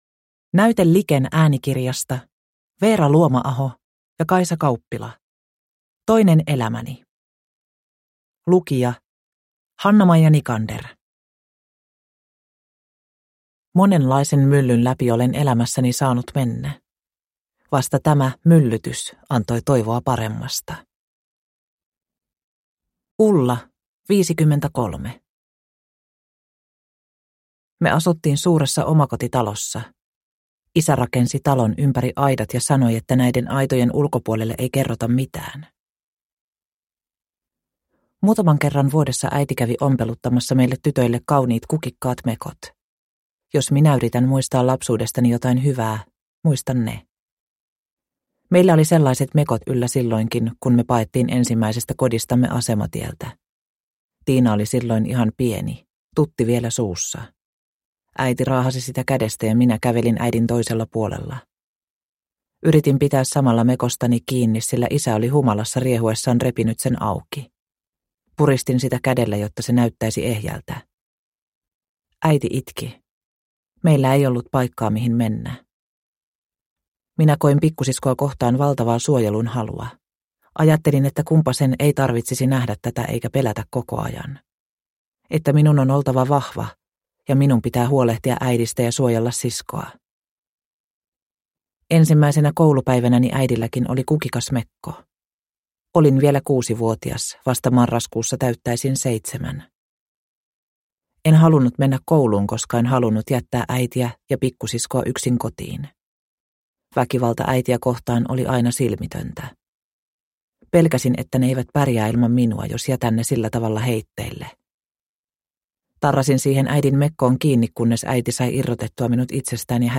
Toinen elämäni – Ljudbok – Laddas ner